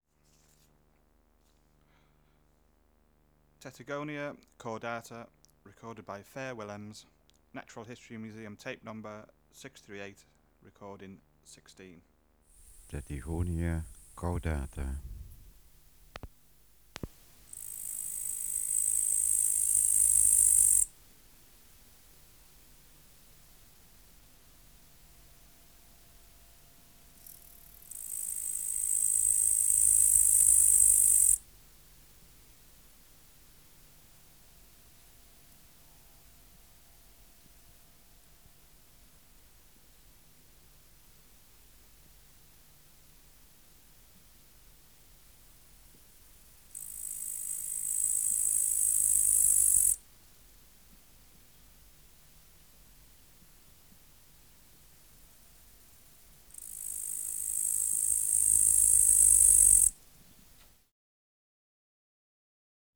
566:18 Tettigonia caudata (638r16) | BioAcoustica
Air Movement: Nil Light: In shadow Substrate/Cage: In cage
Isolated male
Microphone & Power Supply: AKG D202 (LF circuit off) Distance from Subject (cm): 15